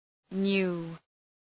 Προφορά
{nu:} – αόρ. του ‘know’